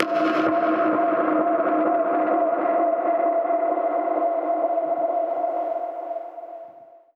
Index of /musicradar/dub-percussion-samples/134bpm
DPFX_PercHit_A_134-09.wav